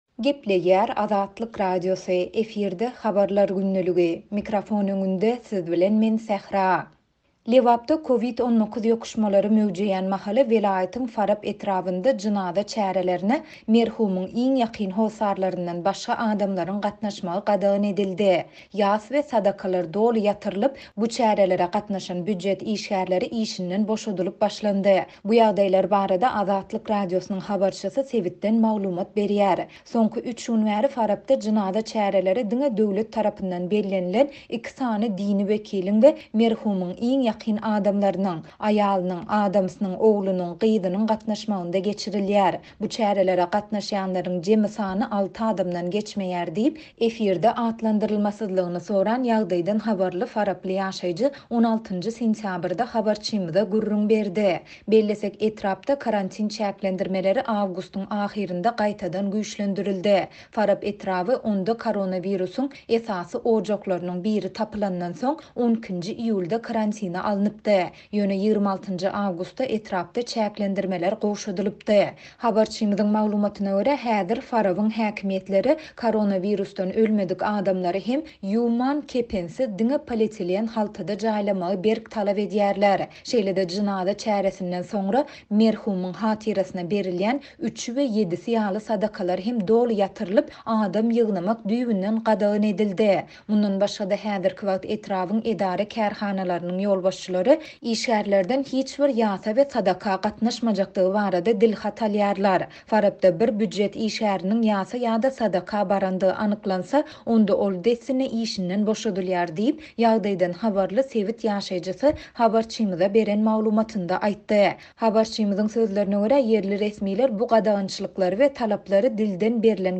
Bu ýagdaýlar barada Azatlyk Radiosynyň habarçysy sebitden maglumat berýär.